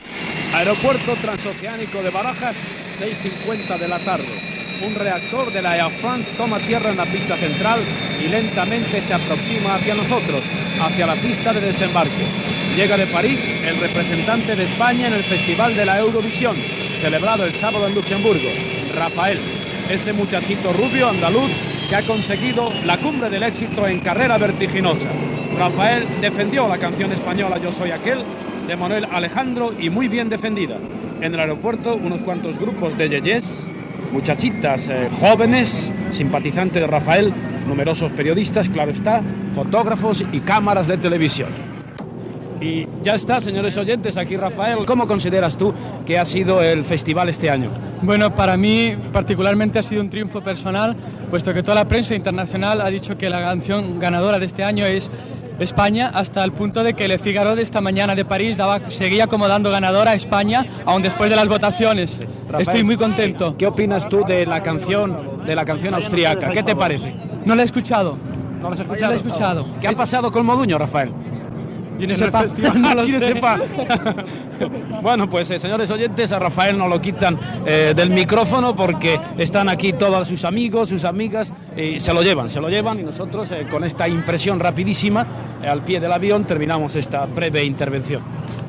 Informació des de l'aeroport de Barajas (Madrid) de l'arribada del cantant Raphael (Rafael Martos) que havia participat al Festival d'Eurovisió celebrat a Luxemburg